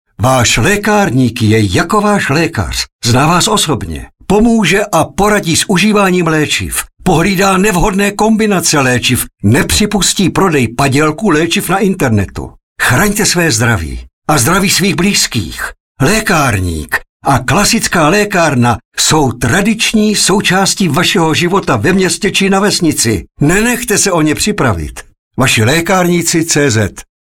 Svůj hlas kampani propůjčil herec Ladislav Frej. Spoty, které můžete od dnešního dne slýchat na Frekvenci 1 a Rádiu Blaník, si můžete poslechnout níže.